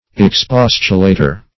Expostulator \Ex*pos"tu*la`tor\ (?;135), n. One who expostulates.